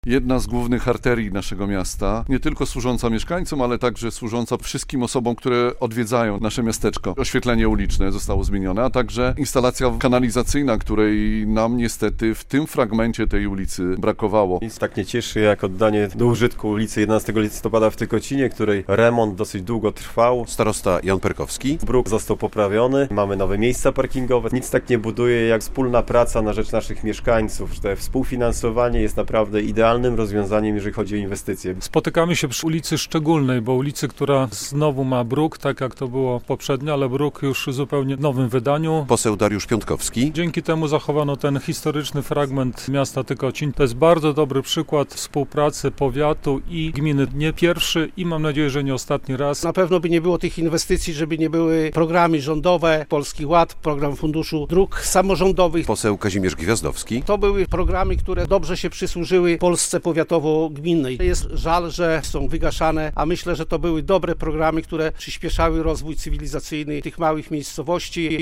Otwarcie ulicy 11 Listopada w Tykocinie - relacja